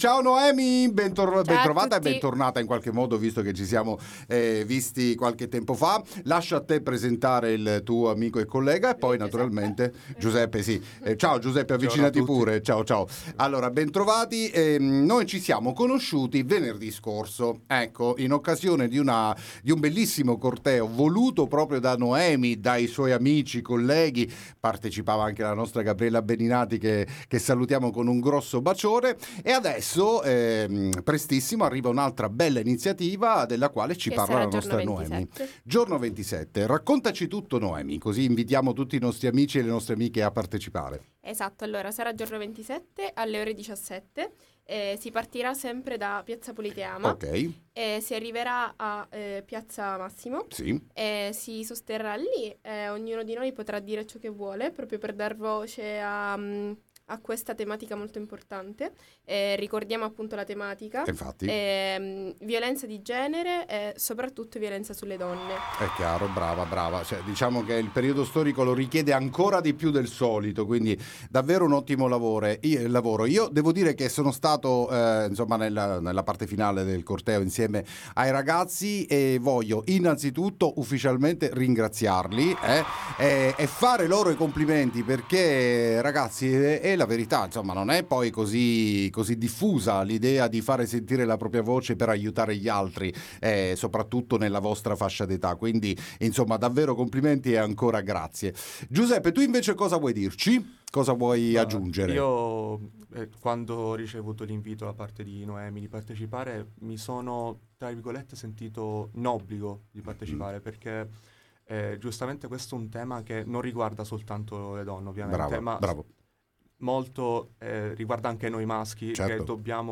Corteo contro le violenze domenica 27/04/2025 alle 17:00: intervistiamo i fondatori del progetto